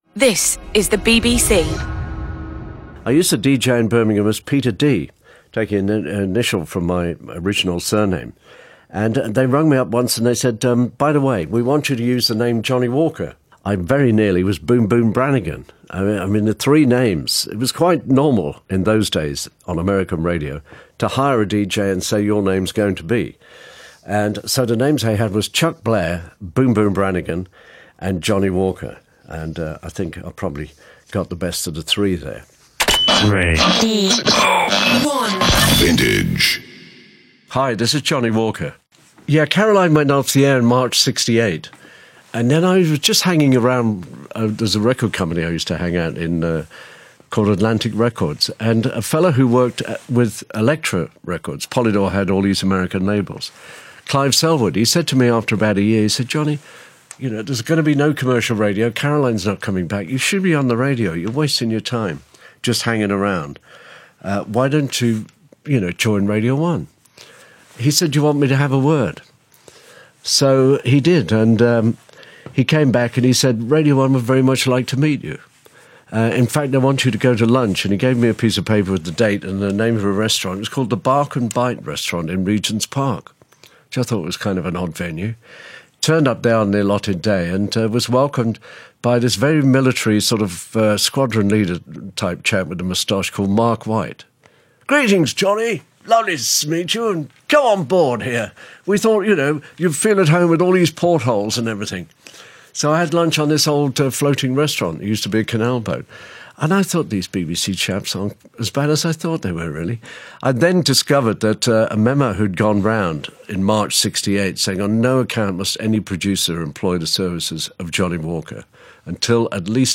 johnnie walker radio one vintage interview podcast